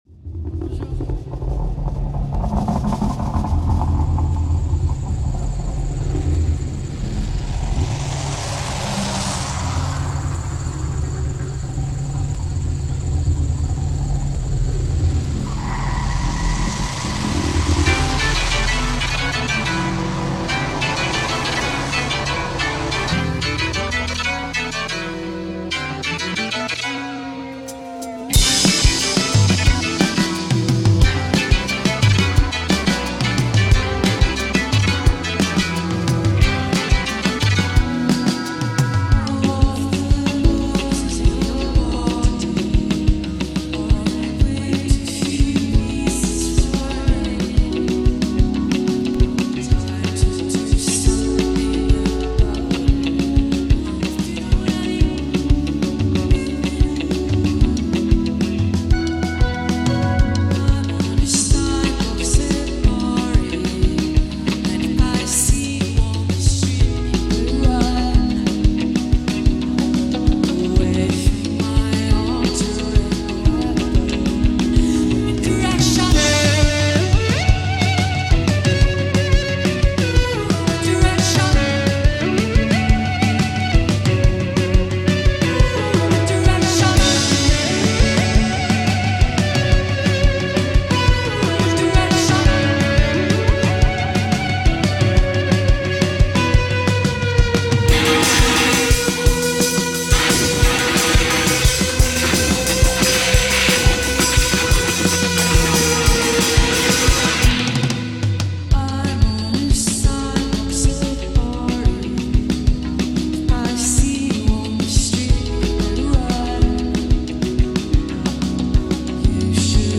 Electronic/Experimental